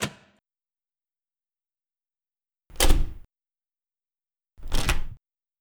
Wall switch action SFX
wall_switch.wav